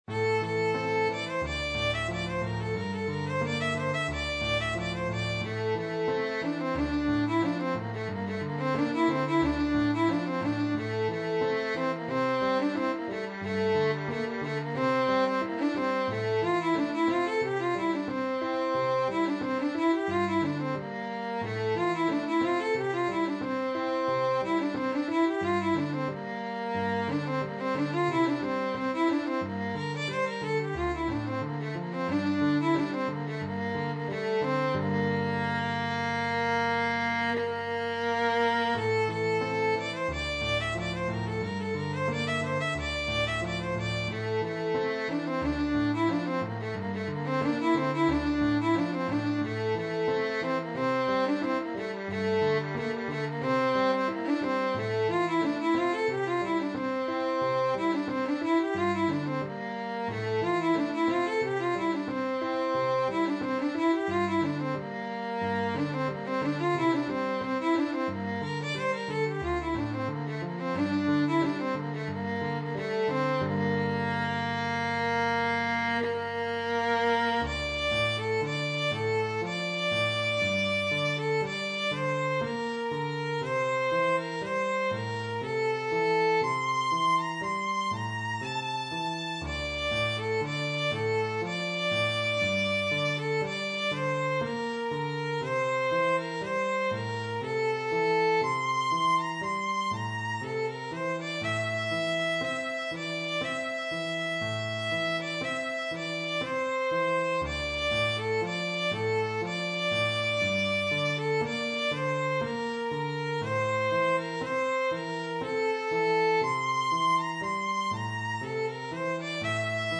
سطح : متوسط
ویولون